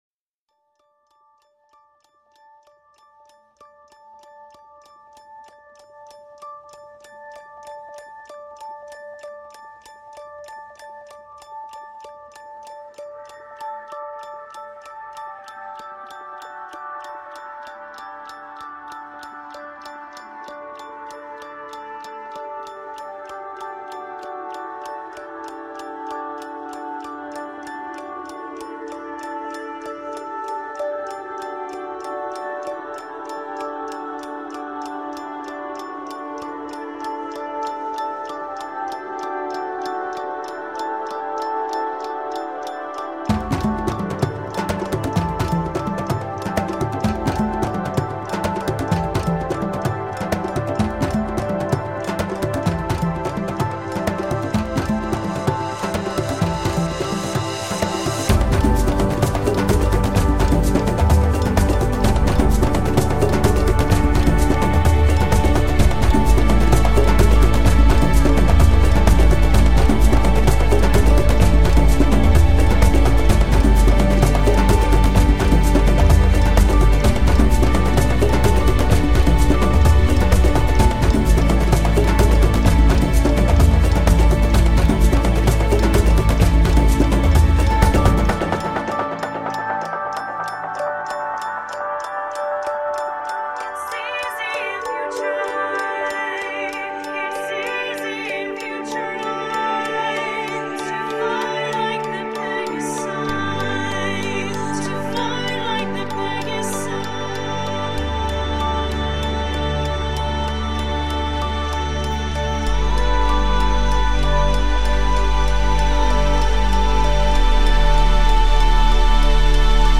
essentially a remix